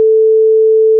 En comparaison avec le monde du son, imaginons la note LA.
Son d'une courbe parfaite
Parmi l'exemple des sons que nous vous proposons, vous pouvez distinguer un son pur qui se limite à une seule sinusoïde.